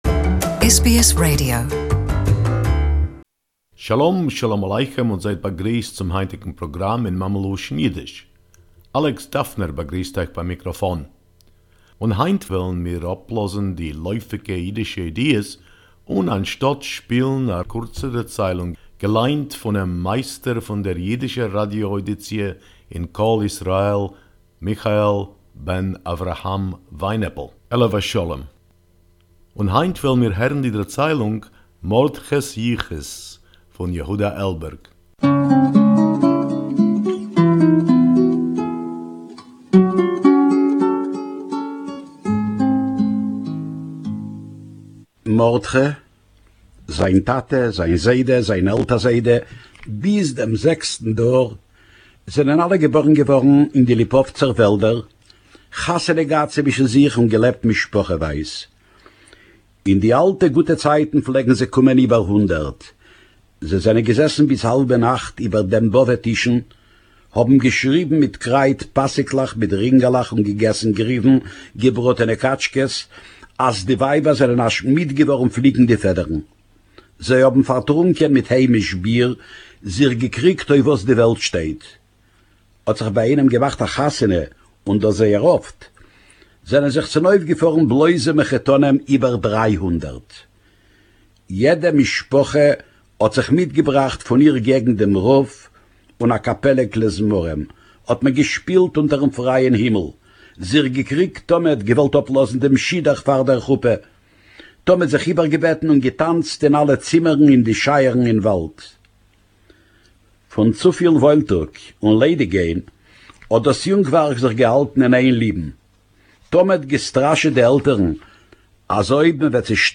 Yiddish Story